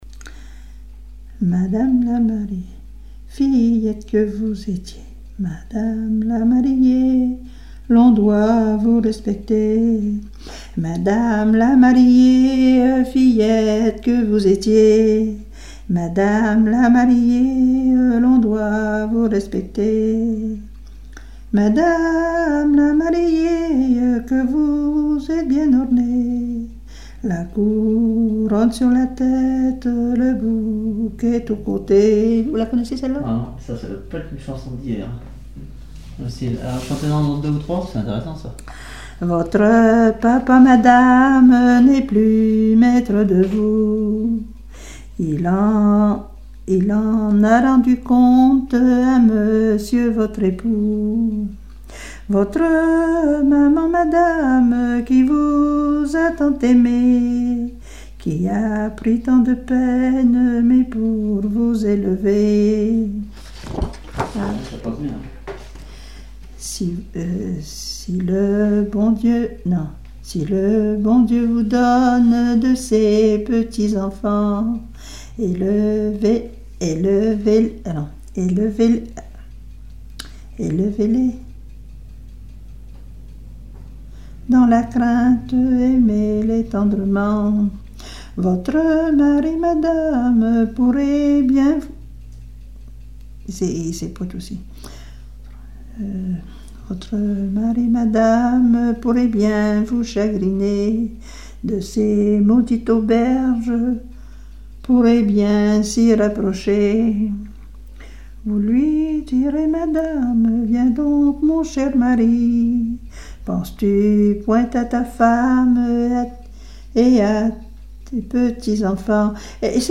circonstance : fiançaille, noce
Genre strophique
Pièce musicale inédite